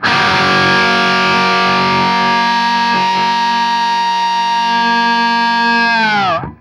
TRIAD F#  -L.wav